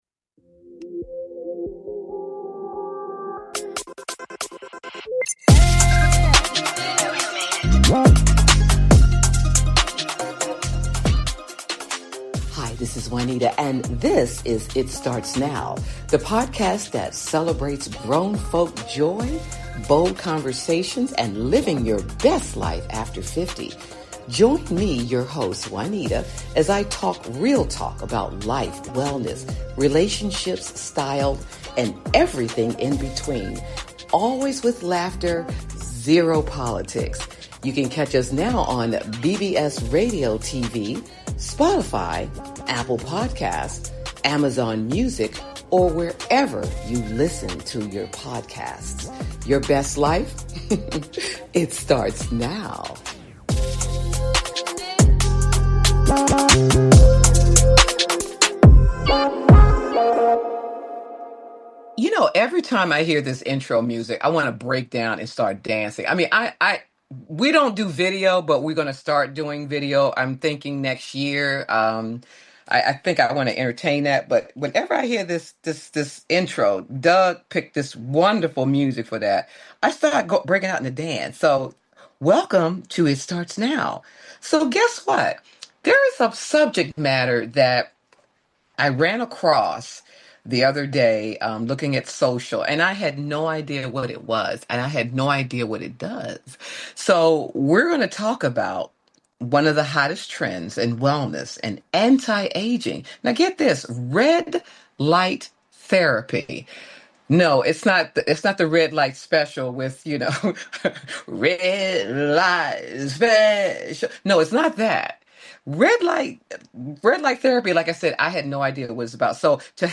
It Starts Now Talk Show